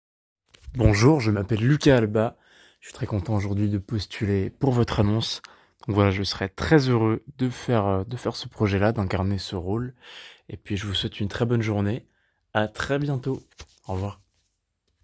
Présentation orale